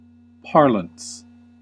Ääntäminen
Synonyymit jargon language Ääntäminen US : IPA : [paʁlɑ̃s] UK : IPA : /ˈpɑː.ləns/ US : IPA : /ˈpɑɹ.ləns/ Haettu sana löytyi näillä lähdekielillä: englanti Käännös Substantiivit 1. puhetapa 2. sanontatapa 3.